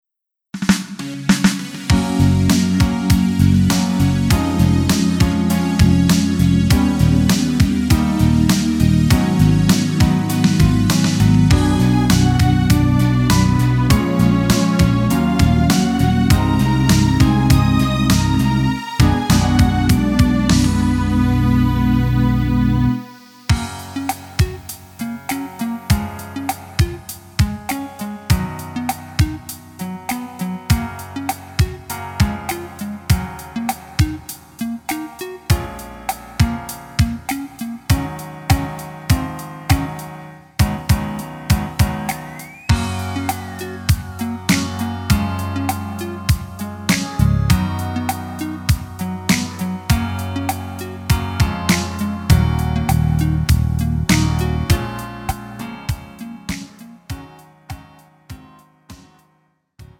음정 남자키
장르 구분 Pro MR